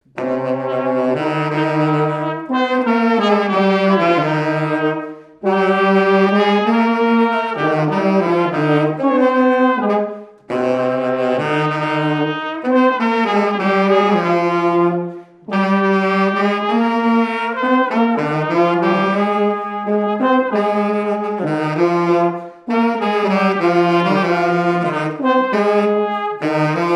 Marche de noce No 1
Localisation Champagné-les-Marais
Pièce musicale inédite